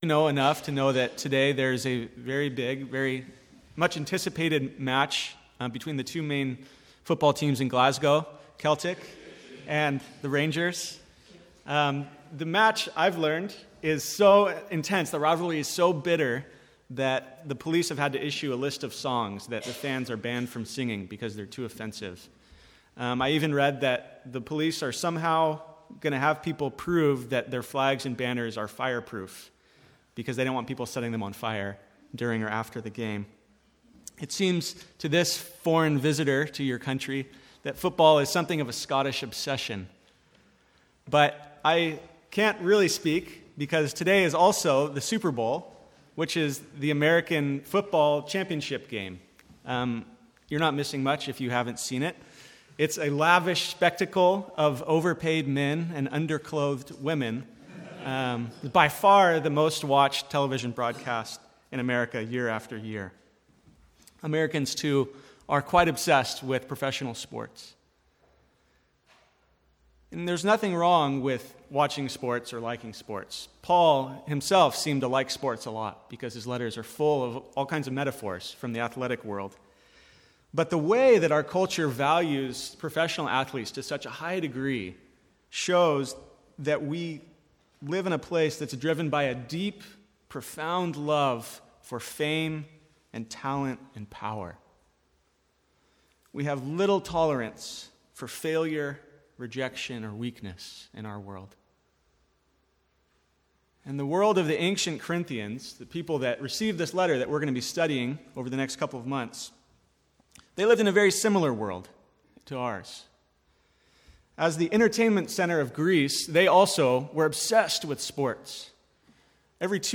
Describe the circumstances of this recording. From the Sunday morning series in 2 Corinthians.